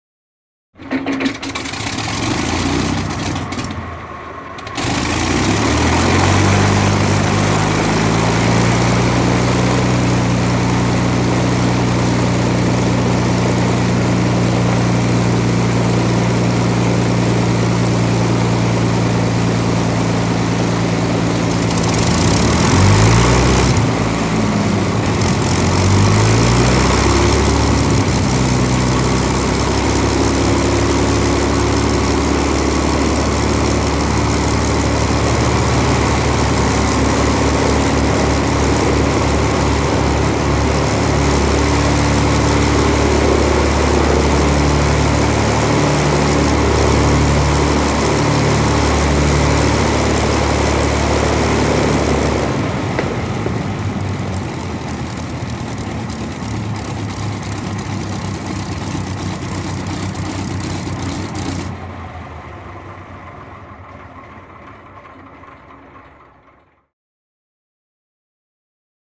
Prop Plane; Idle / Cut; Mosquito Prop Aircraft Constant Engines With Switch Off And Rundown.